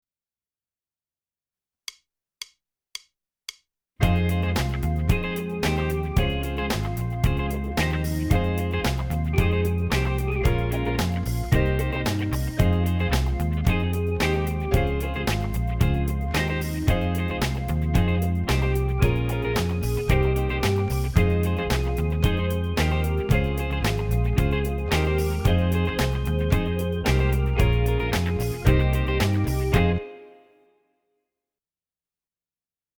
Méthode pour Guitare - Guitare Tablatures